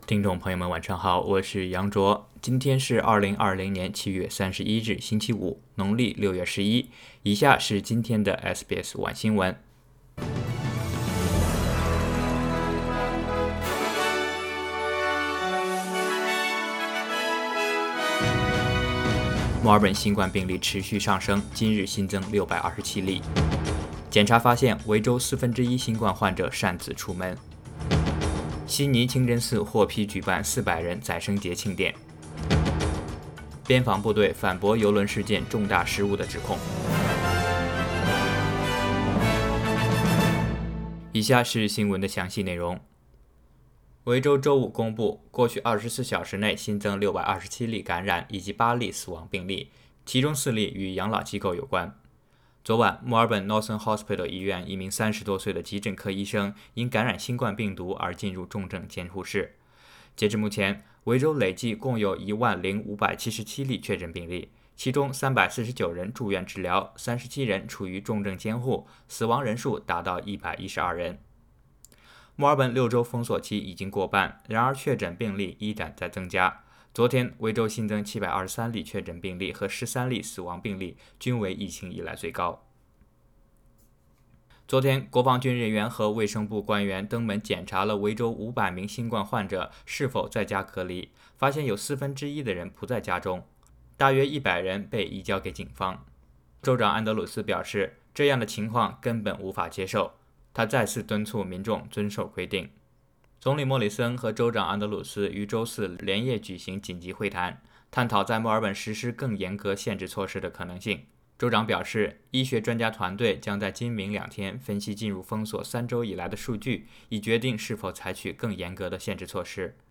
SBS晚新闻（7月31日）